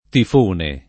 [ tif 1 ne ]